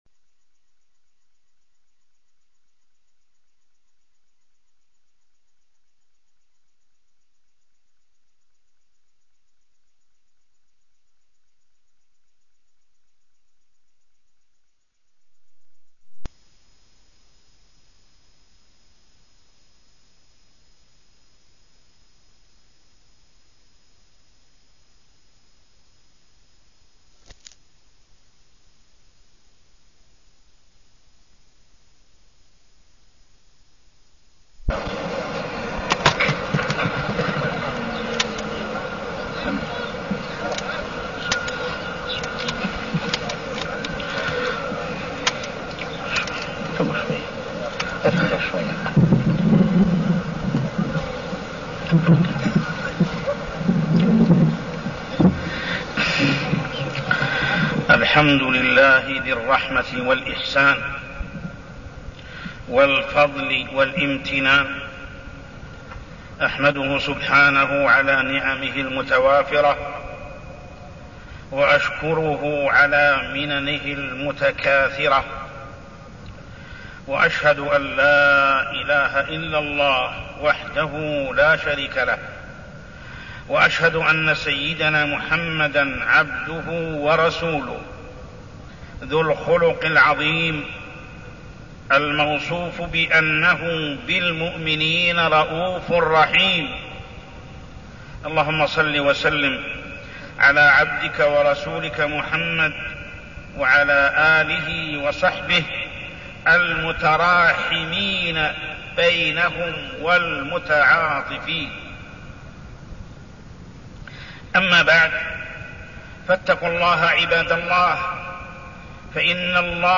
تاريخ النشر ١٥ رجب ١٤١٣ هـ المكان: المسجد الحرام الشيخ: محمد بن عبد الله السبيل محمد بن عبد الله السبيل العطف والإحسان